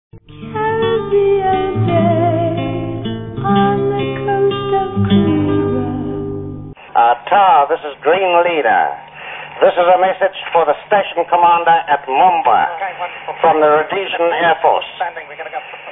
After the first bombing run at Westlands Farm, 'Green Leader' delivered the now famous speech to Lusaka tower.